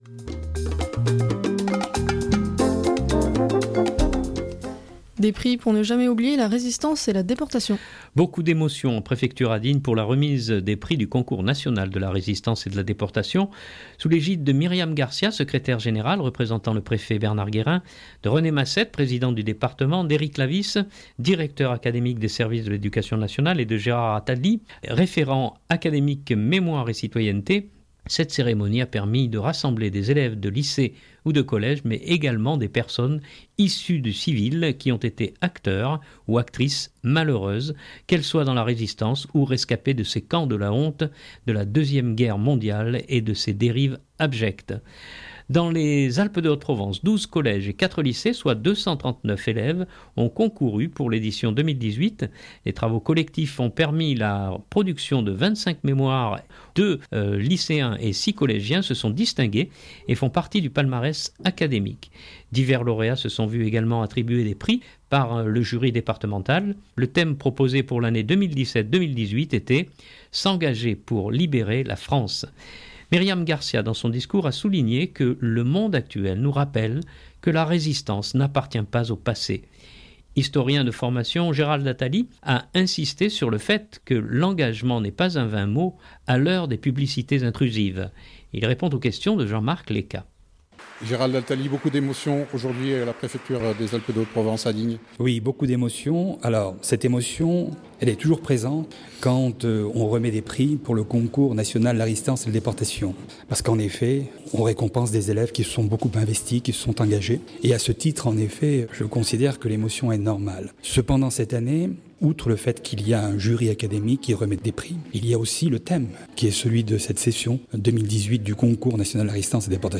Beaucoup d’émotions en Préfecture à Digne pour la remise des prix du concours national de la Résistance et de la Déportation.